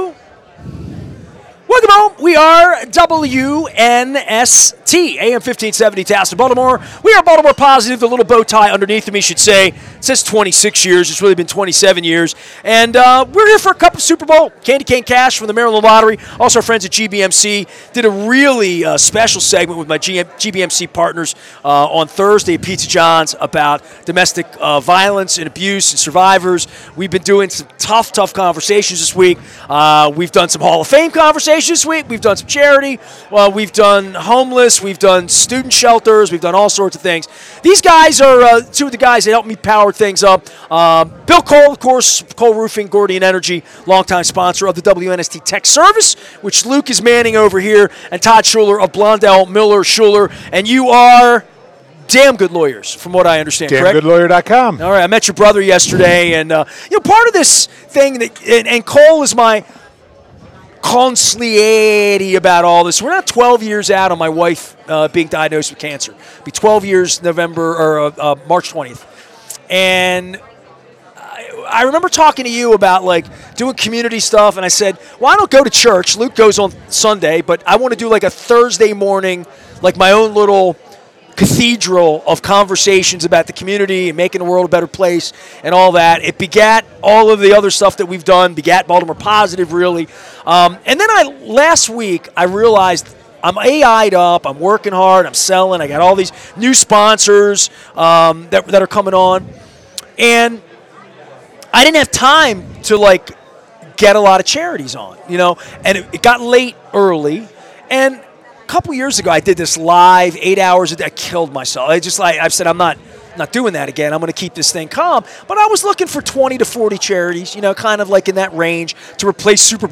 a spirited discussion about listening to girls in modern America from Costas Inn in Timonium.